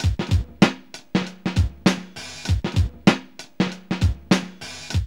FUNKYSWNG 98.wav